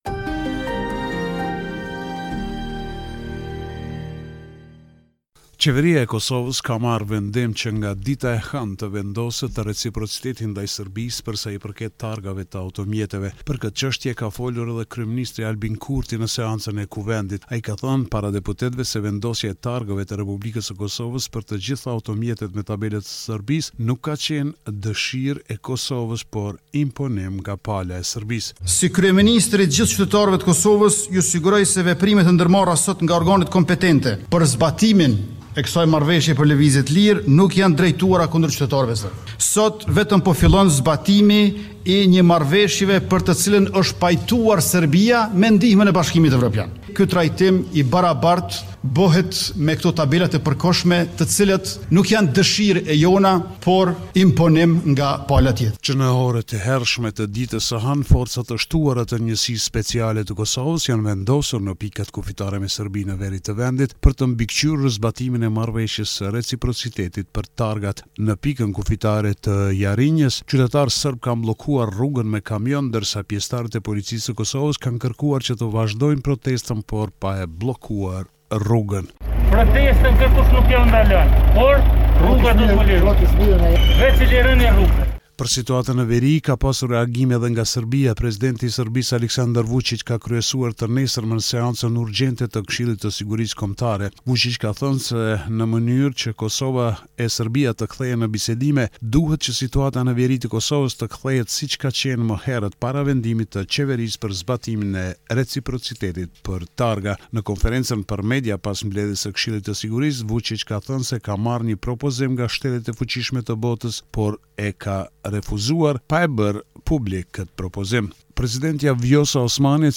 This is a report summarising the latest developments in news and current affairs in Kosova.